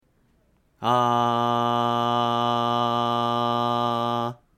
２、胸腔共鳴
声帯を閉じ、喉を開くことで低音の響きが良くなり、落ち着いた声が出せます。
（胸腔共鳴で）アーーーーー
胸腔共鳴.mp3